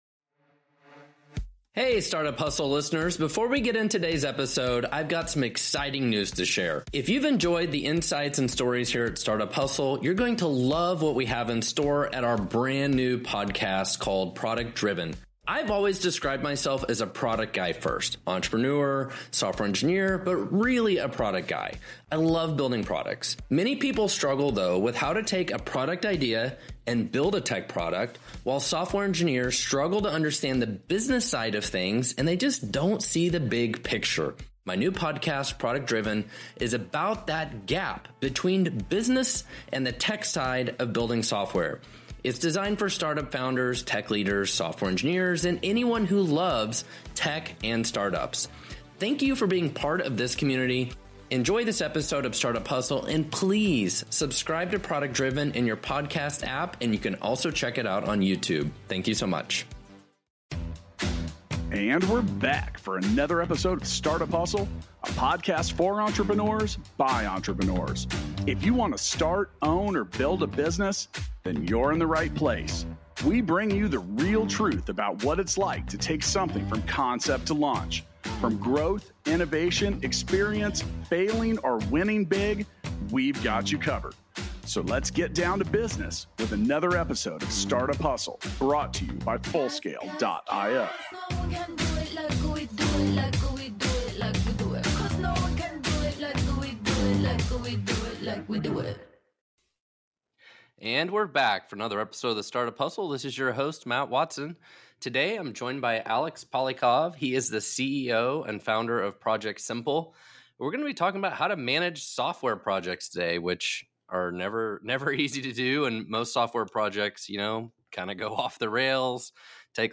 Listen to their entertaining discussion about conflicts between product development and engineering needs. Gain insights into effective productivity tracking and strategies for market persuasion. Plus, unravel the complexities of technical debts.